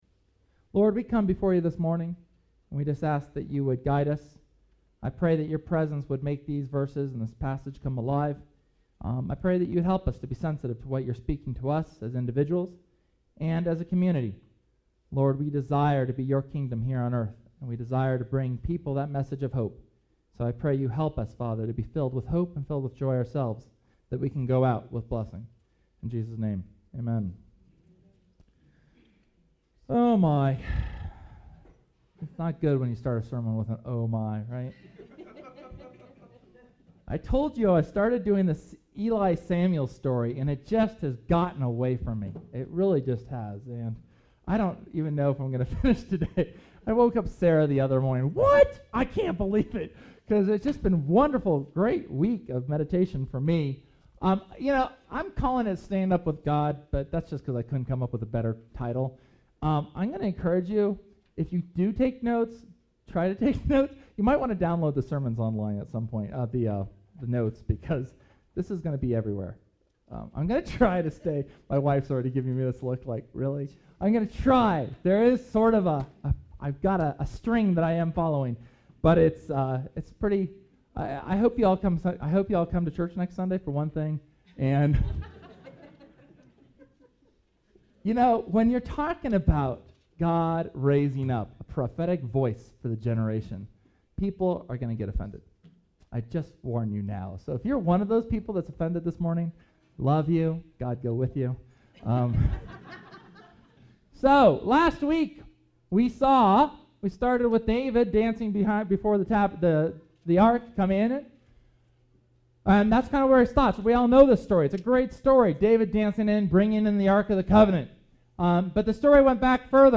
Sunday, December 2nd's sermon; a continuation of the life of Samuel and Israel.